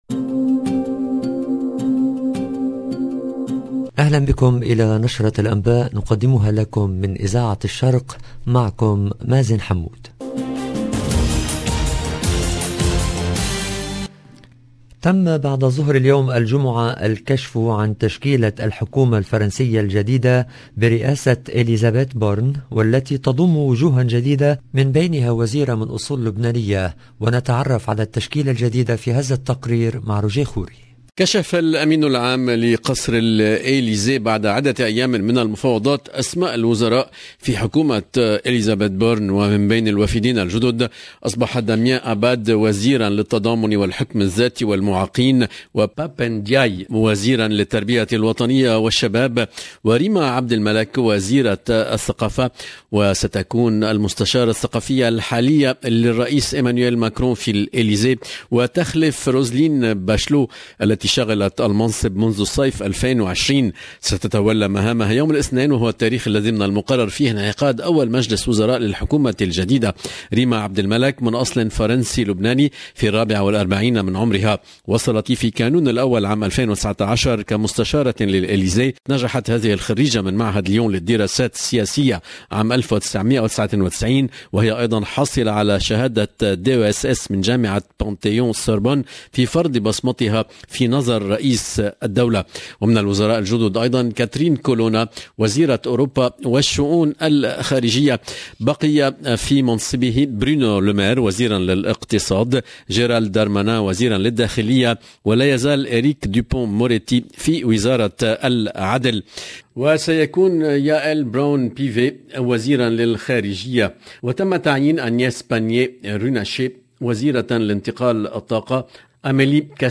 LE JOURNAL DU SOIR EN LANGUE ARABE DU 20/05/22